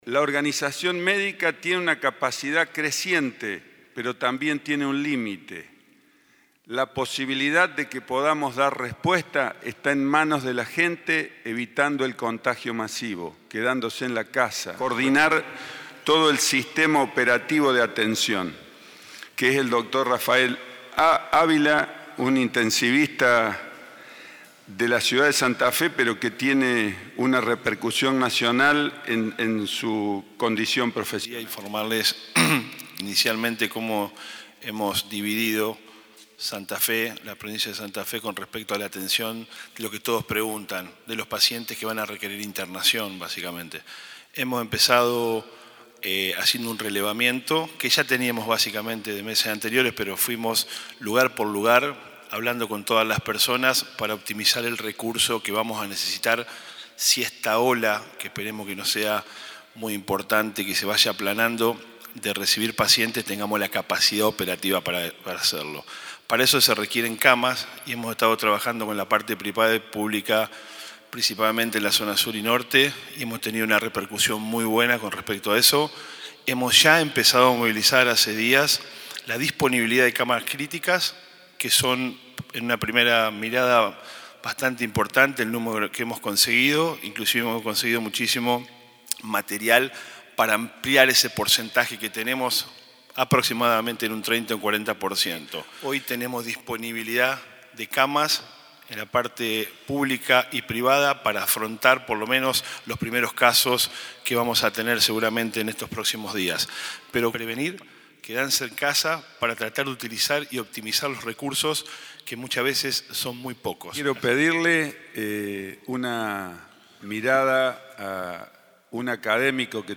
El ministro de Salud, Carlos Parola, brindó un informe de la situación provincial frente a la pandemia por Covid-19
Conferencia de prensa Sábado 21 de marzo 10:30 hs.